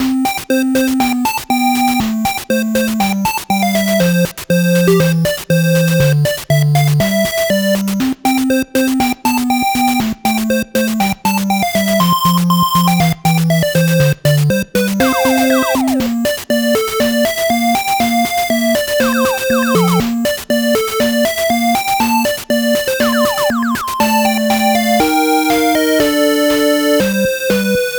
8bit（レトロゲーム風）音源
【イメージ】元気、マーチ など